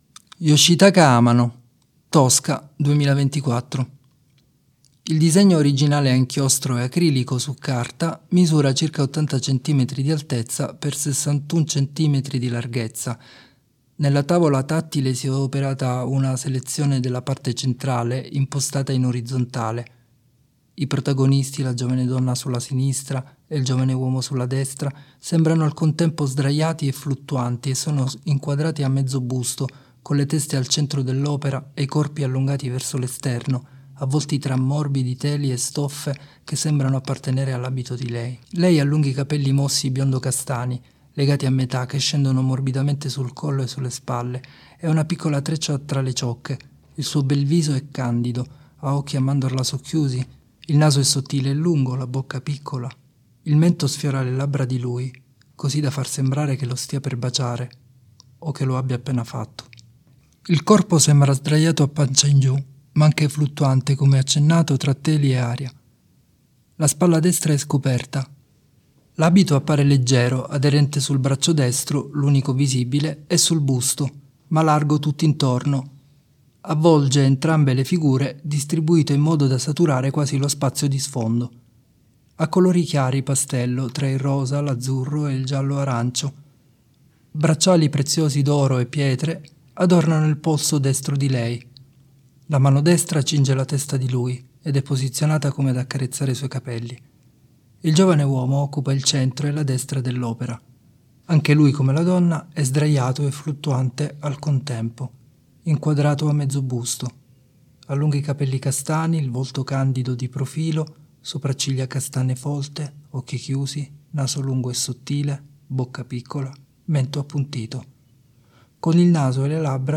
Descrizioni pannelli sensoriali per ciechi e ipo-vedenti: